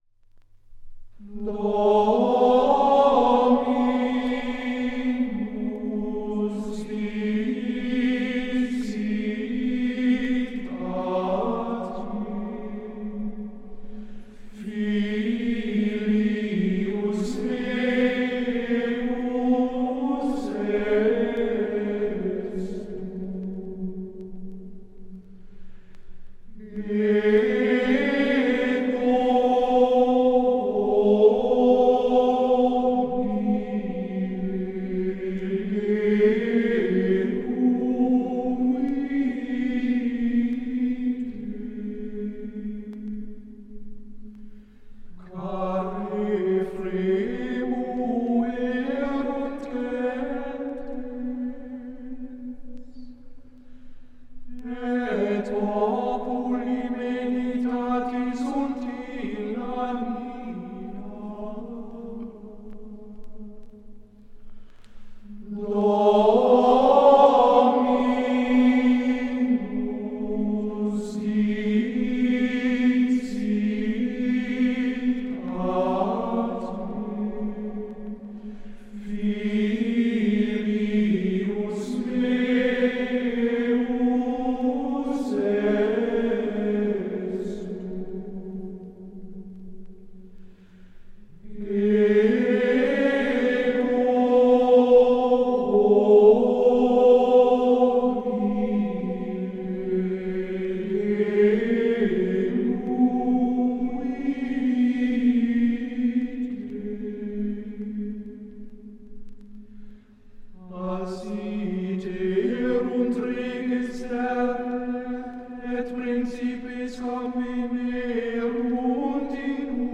Gregorianischen Chorals